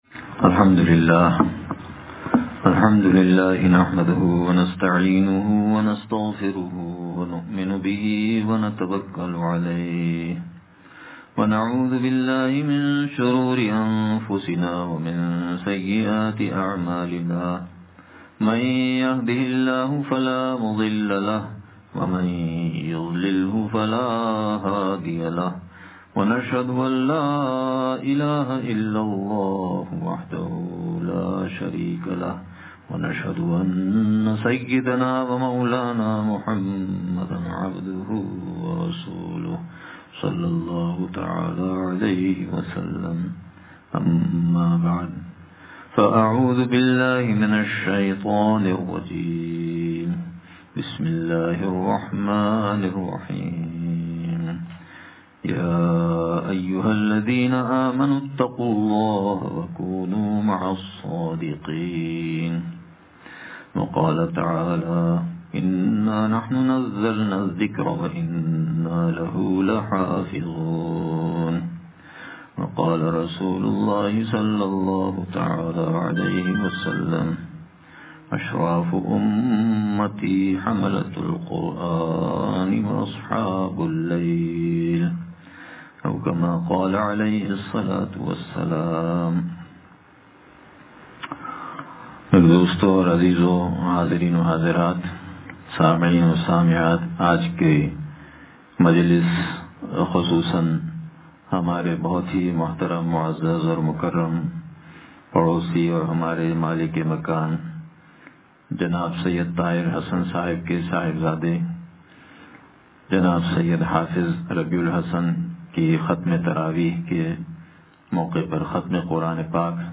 ختم قرآن کے موقع پر خصوصی بیان – دنیا کی حقیقت – نشر الطیب فی ذکر النبی الحبیب صلی اللہ علیہ وسلم